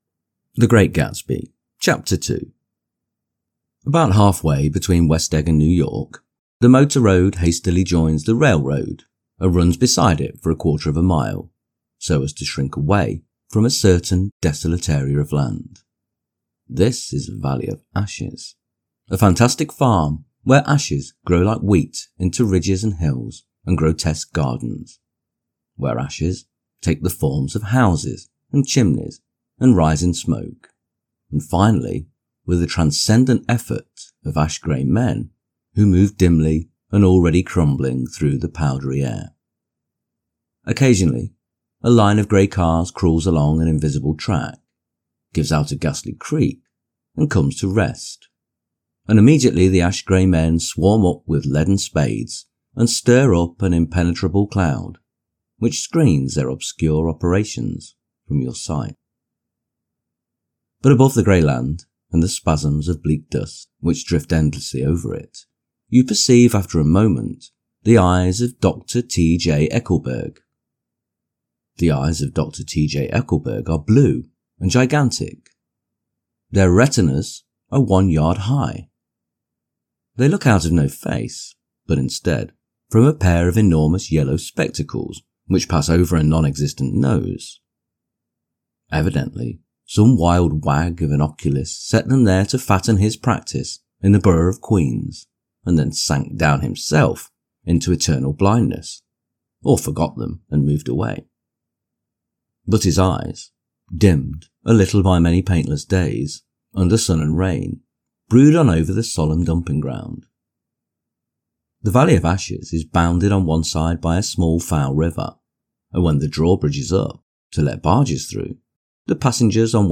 The Great Gatsby Audio-book – Chapter 2 | Soft Spoken English Male Full Reading (F.Scott Fitzgerald) - Dynamic Daydreaming